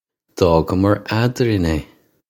Pronunciation for how to say
Daw-gummar ad-reen ay
This is an approximate phonetic pronunciation of the phrase.